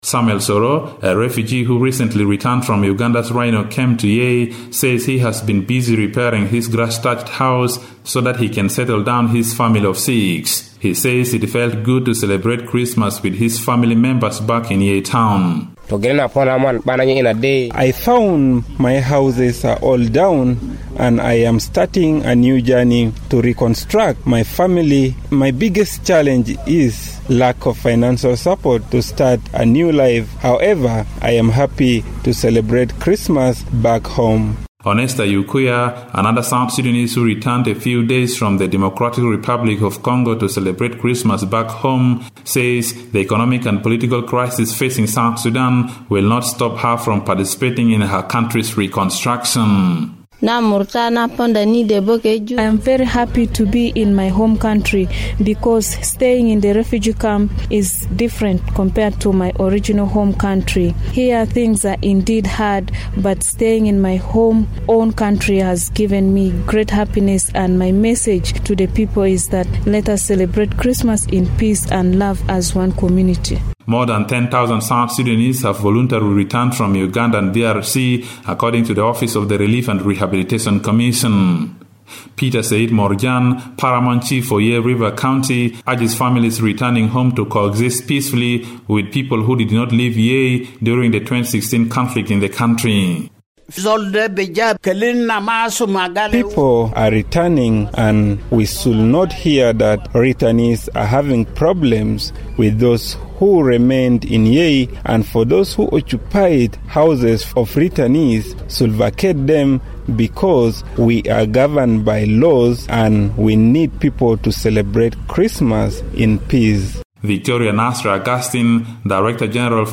spoke to refugees in Yei who said they are happy to be home despite challenges.